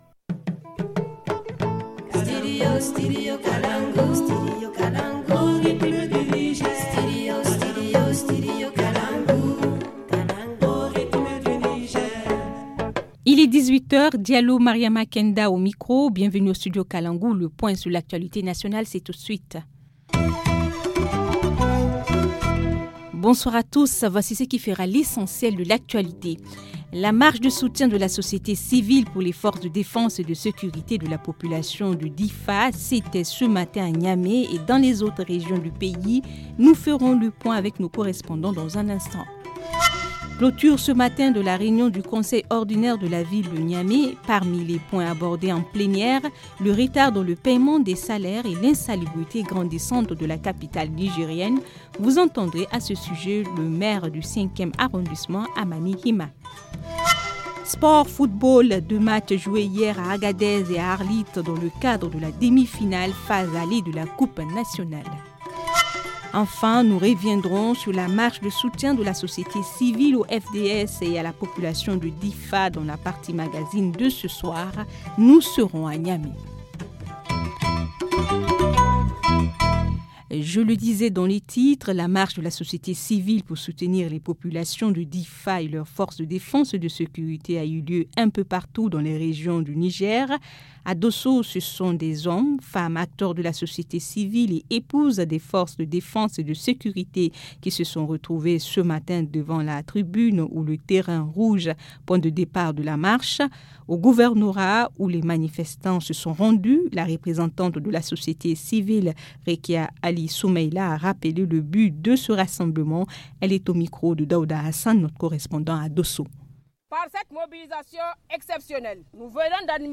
2. Clôture ce matin de la réunion du Conseil ordinaire de la ville de Niamey. Parmi les points abordés en plénière: le retard dans le paiement des salaires et l’insalubrité grandissante de la capitale Nigerienne vous entendrez à ce sujet le maire du 5ème arrondissement HAMANI Hima.